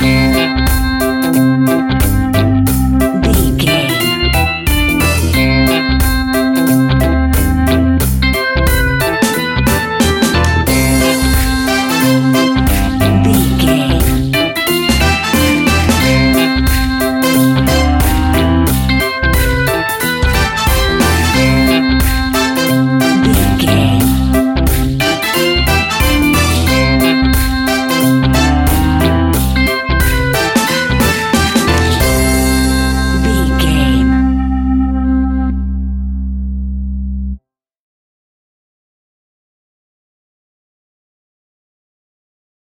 Take me back to the old skool retro seventies reggae sounds!
Ionian/Major
laid back
chilled
off beat
drums
skank guitar
hammond organ
percussion
horns